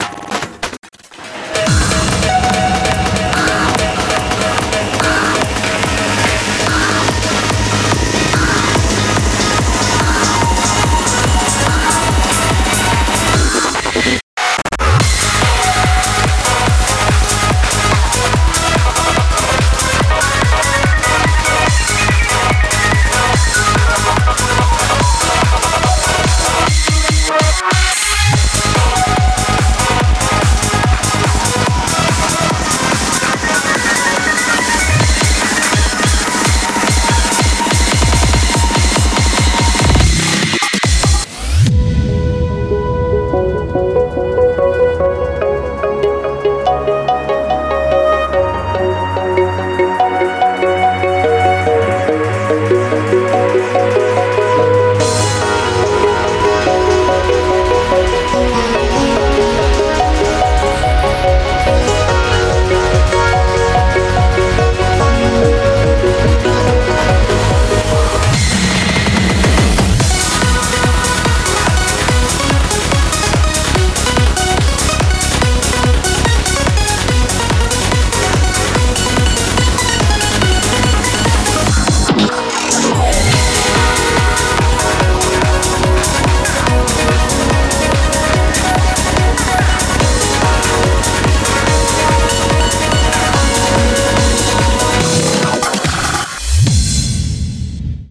BPM36-576
Audio QualityLine Out
Note: it's the lineout, so it's not top quality.